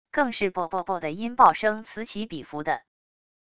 更是啵啵啵的音爆声此起彼伏的wav音频